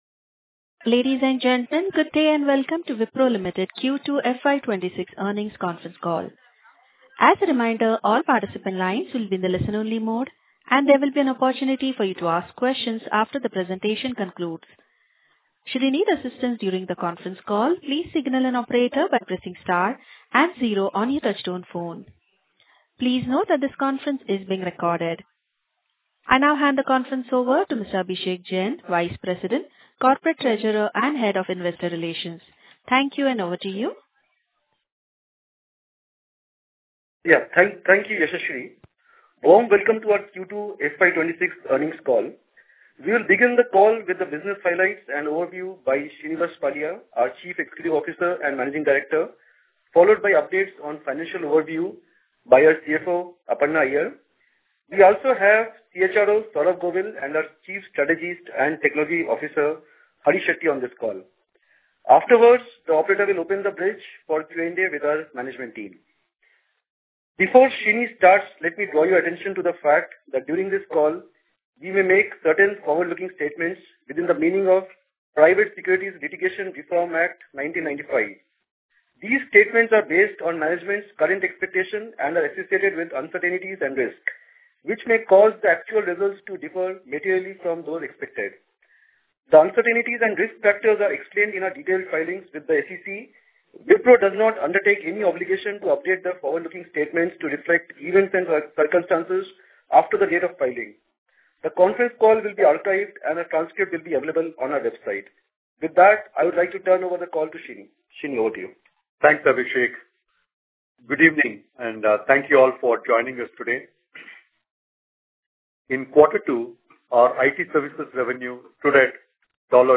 earnings-call-q2fy26.mp3